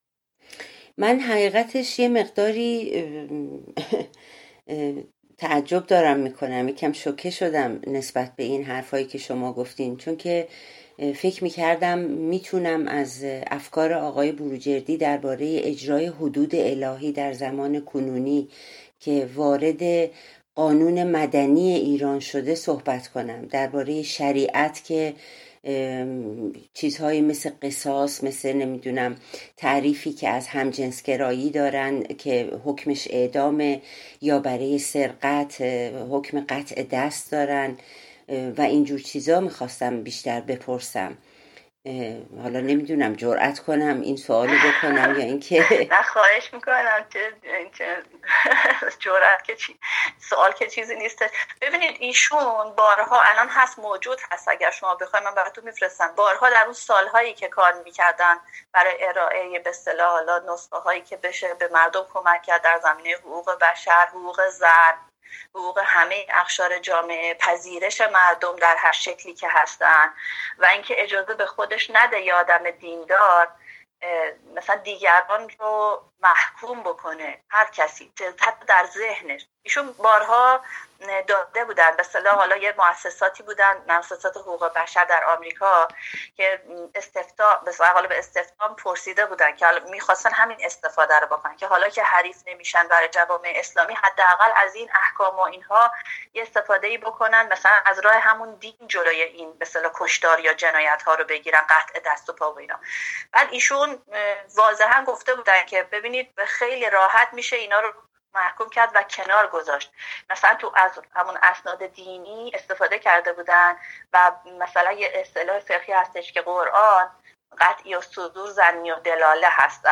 بخش سوم گفت و شنود